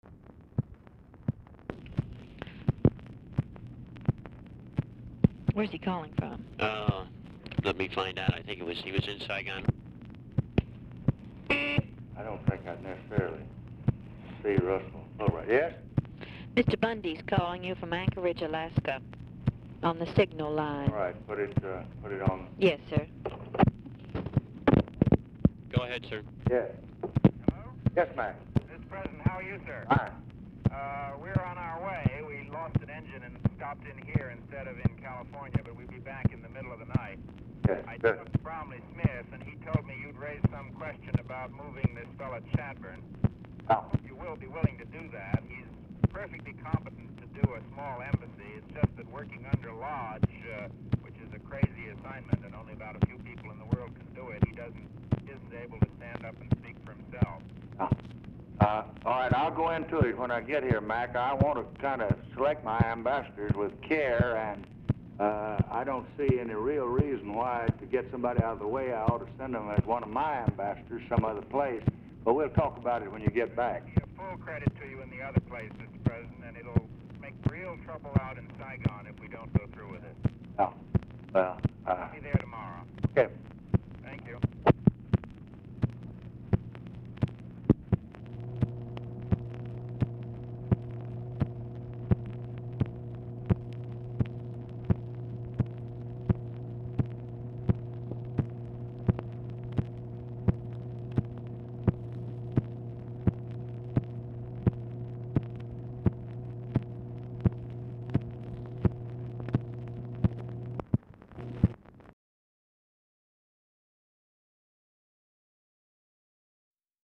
OFFICE SECRETARY ASKS SIGNAL CORPS OPERATOR WHERE BUNDY IS CALLING FROM; BRIEF OFFICE CONVERSATION PRECEDES CALL
Format Dictation belt
Specific Item Type Telephone conversation Subject Appointments And Nominations Defense Diplomacy Vietnam